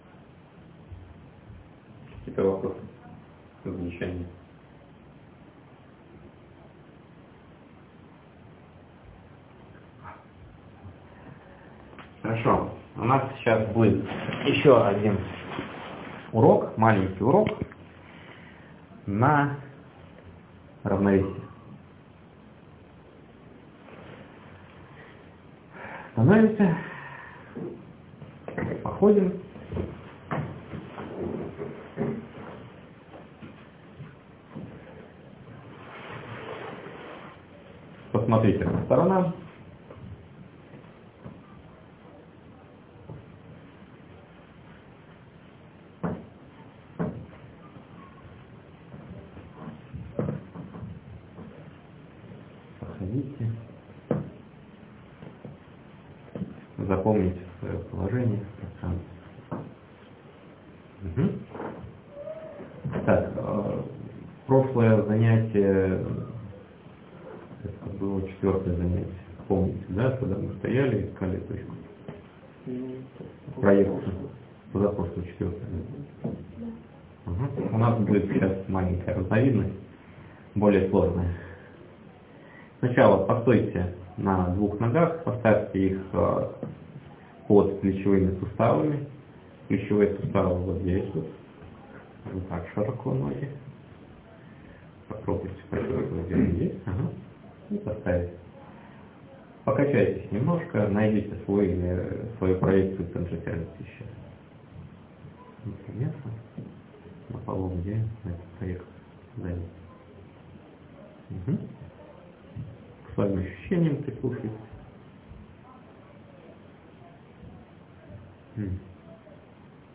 Мини-урок на нахождение своего центра тяжести
В прикрепленном аудиофайле - процесс прохождения урока.